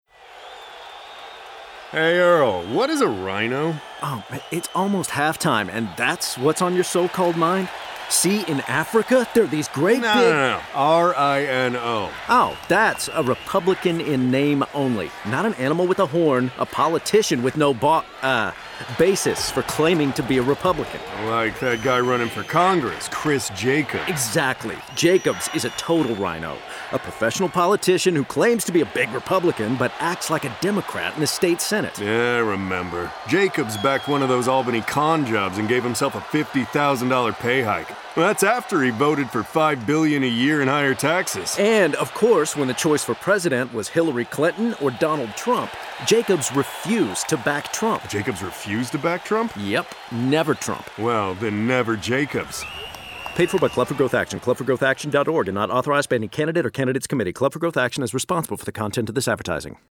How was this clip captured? The television ad will run Monday, October 21 through Friday, October 25 on Fox News in the Buffalo, NY market, and the radio ad will air on WBEN during the same time period.